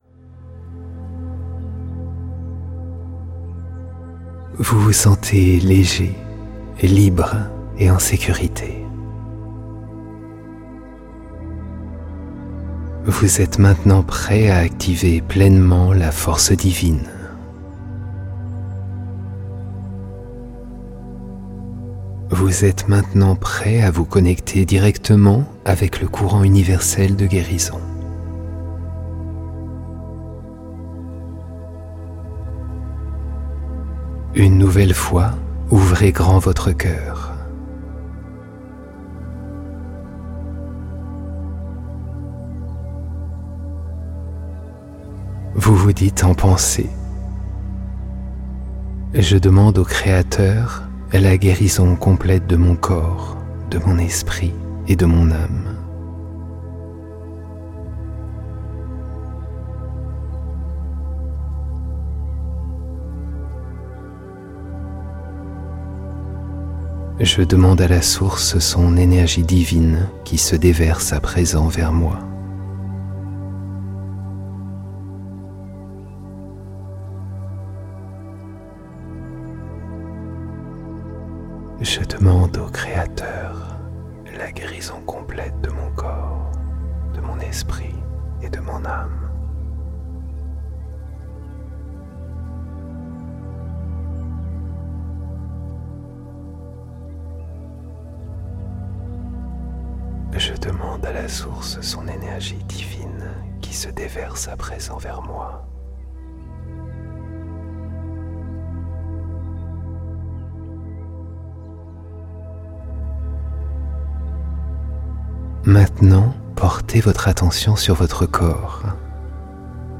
Le pouvoir du courant guérisseur - Relaxation profonde guidée pour activer les forces d'auto-guérison